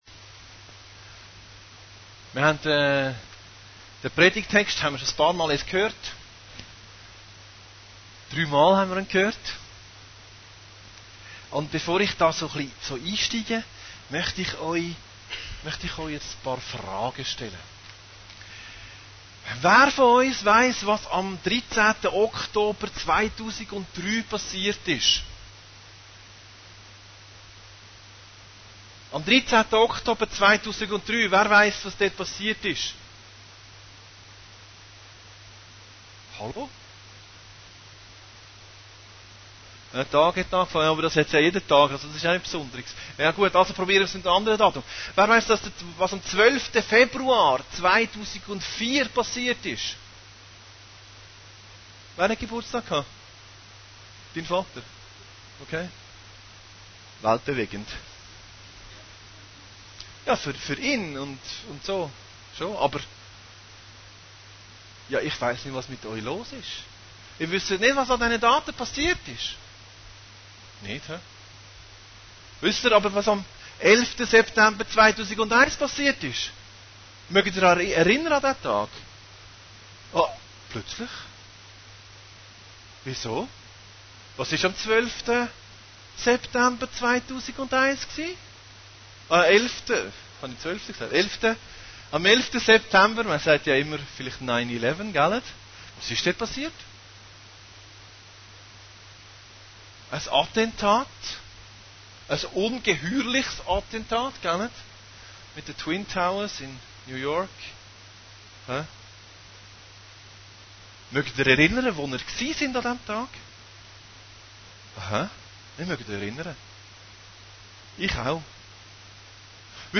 Predigten Heilsarmee Aargau Süd – Die Gemeinde 2